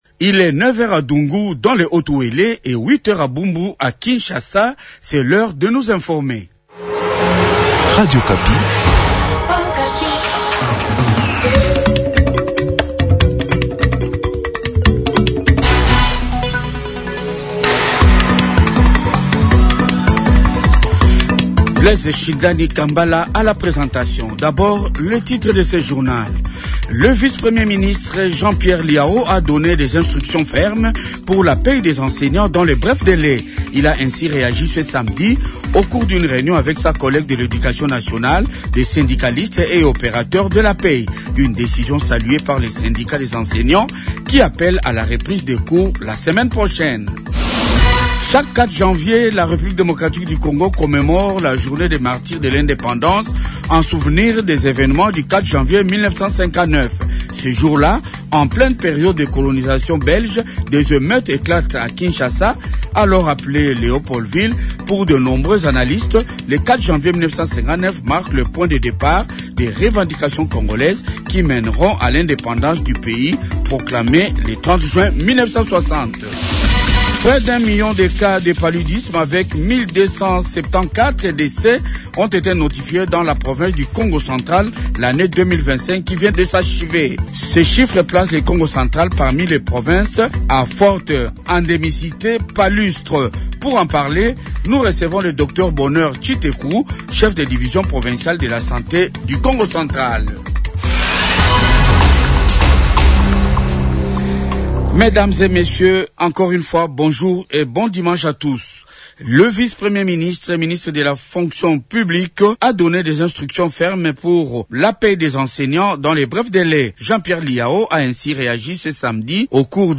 Journal de 8h